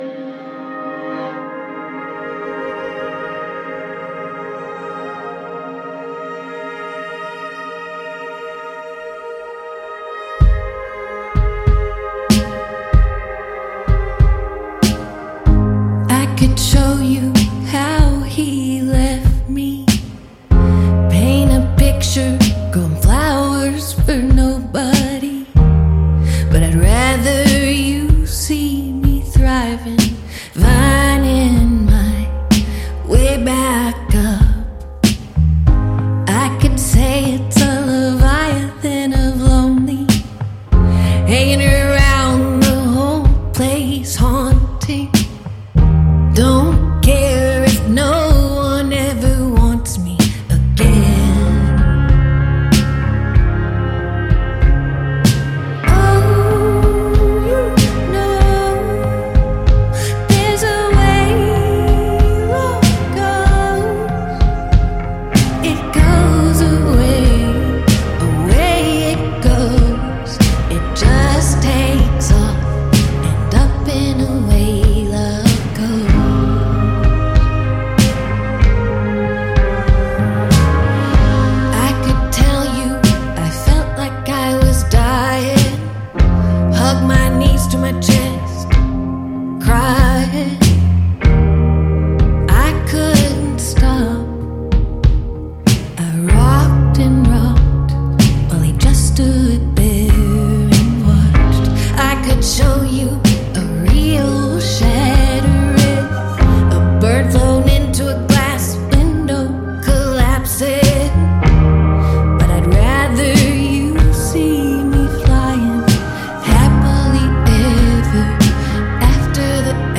кантри